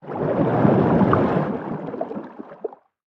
Sfx_creature_arcticray_swim_slow_02.ogg